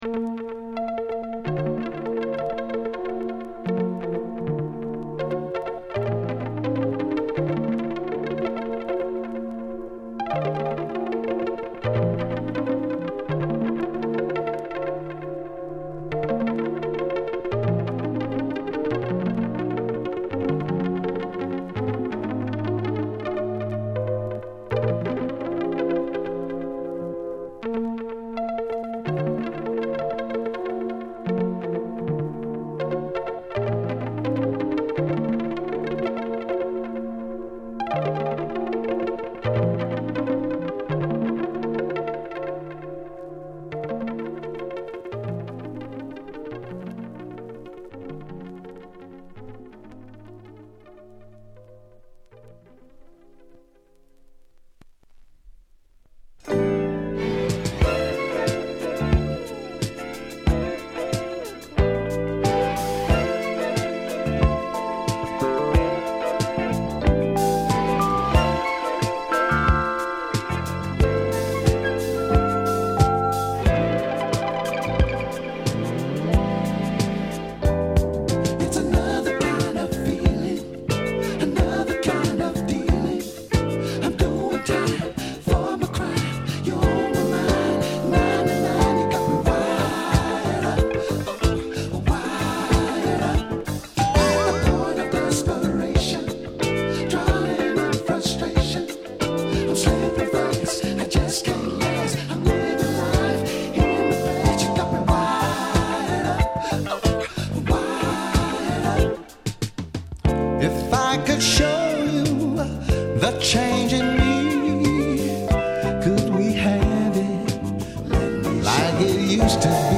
グルーヴィDISCO
Great Modern Soul!!